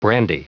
Prononciation du mot brandy en anglais (fichier audio)
Prononciation du mot : brandy